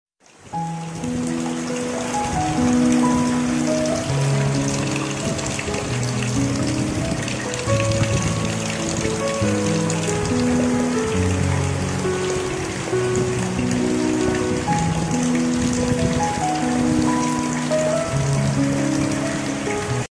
Episod 22 Suasana Desa Yg Sound Effects Free Download